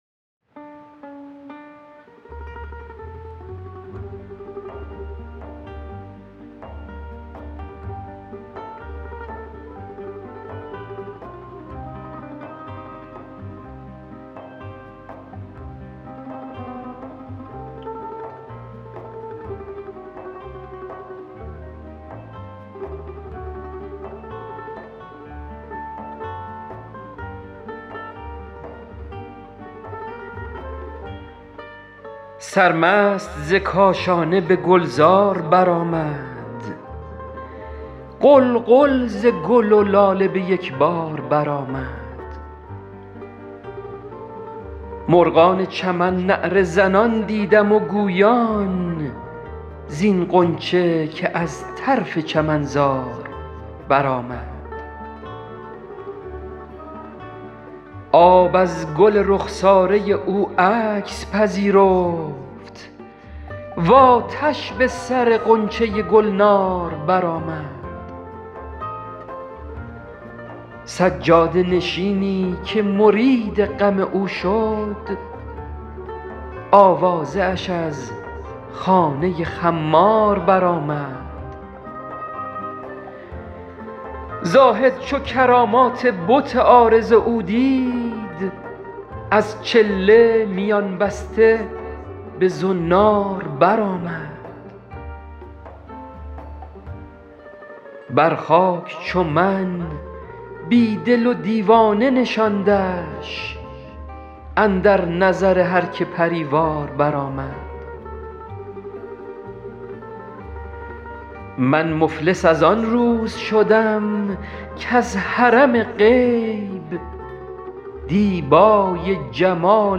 غزل شمارهٔ ۲۱۴ به خوانش